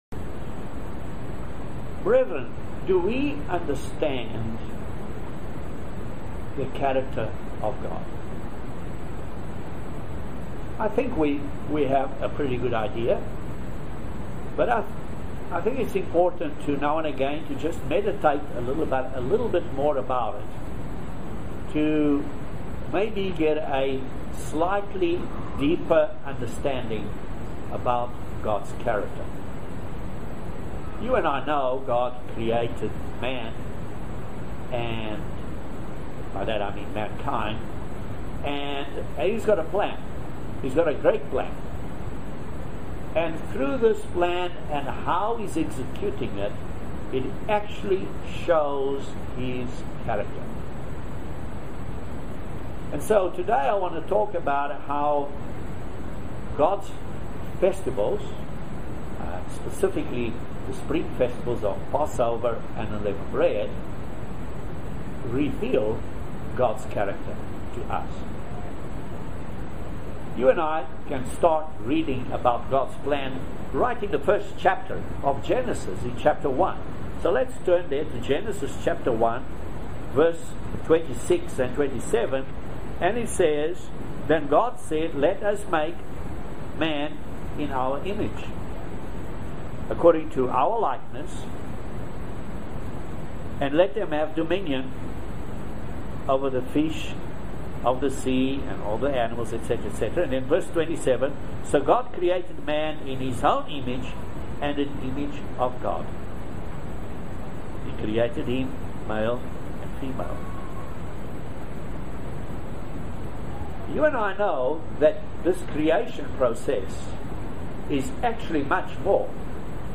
Join us for this very eye opening video Sermon on the subject of God's Character.